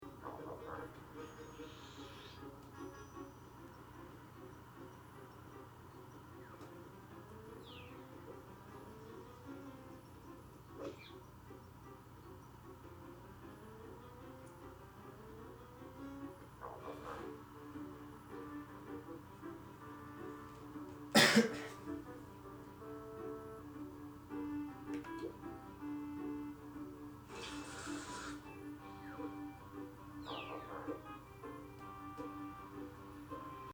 on a windy day. A cough can also be heard.
This entry was posted in Field Recording , Uncategorized and tagged Toontown .